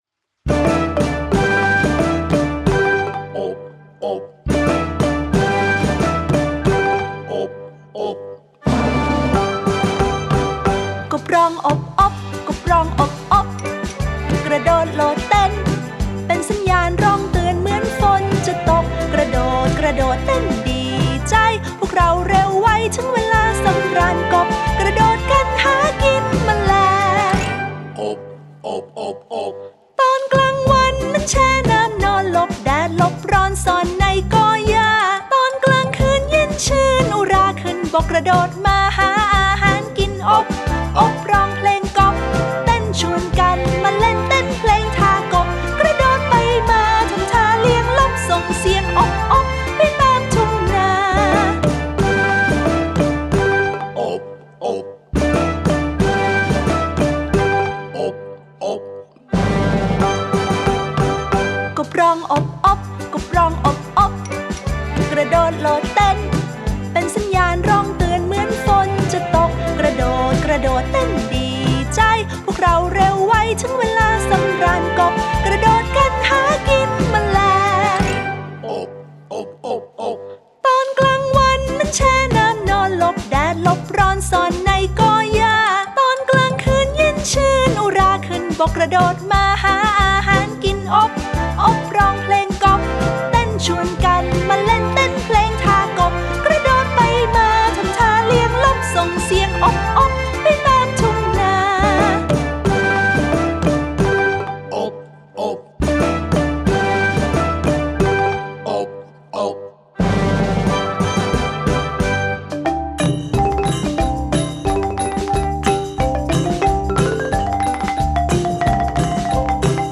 ไฟล์เพลงพร้อมเสียงร้อง